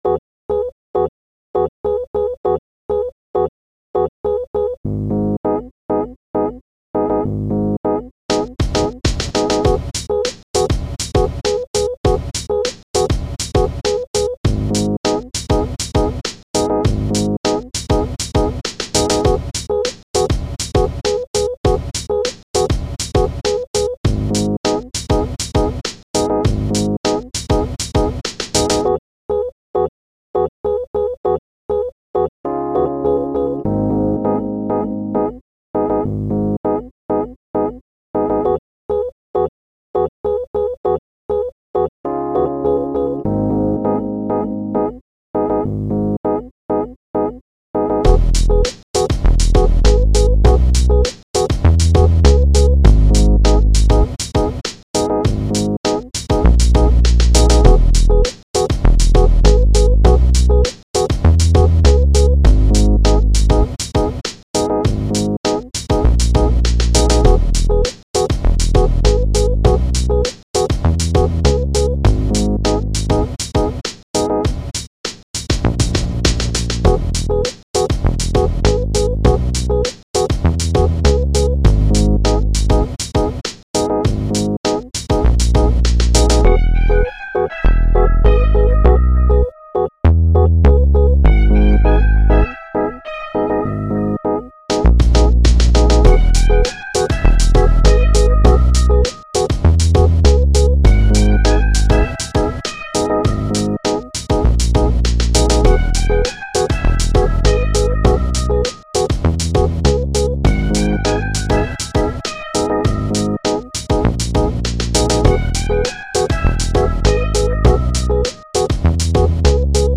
Легкий симпотичный трек. big beat